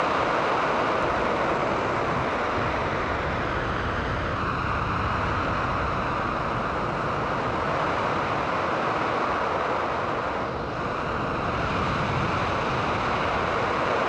mech-armor-flight.ogg